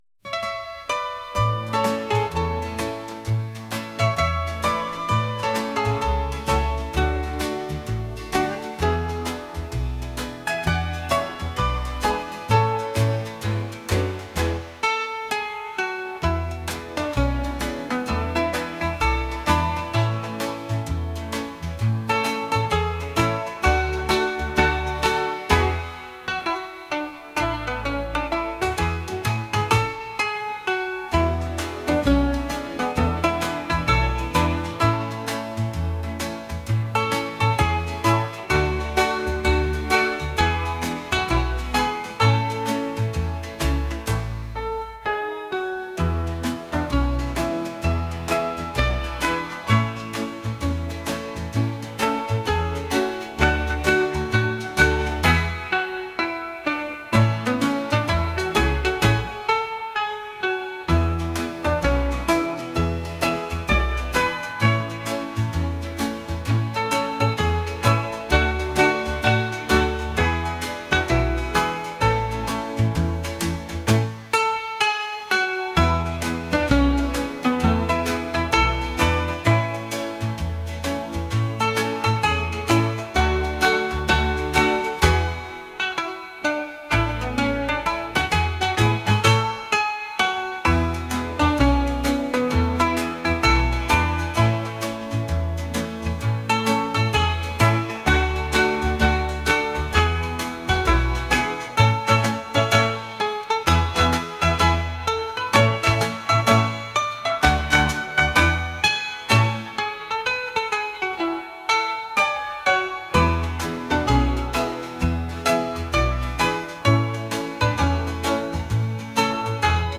pop | acoustic | soulful